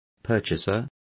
Complete with pronunciation of the translation of purchaser.